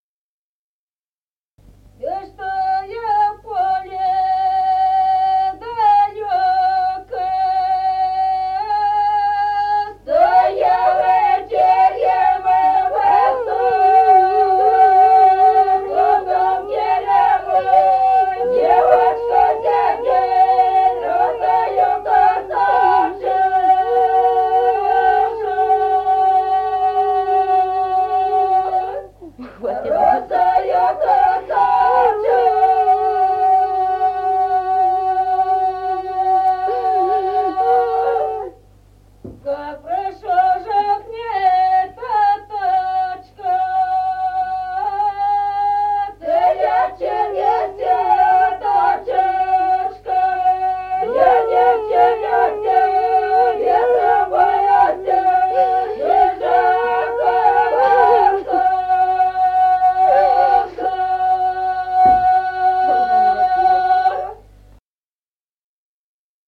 Народные песни Стародубского района «И чтой в поле далёко», свадебная, «невеста нюнит» под песню.
1953 г., с. Остроглядово.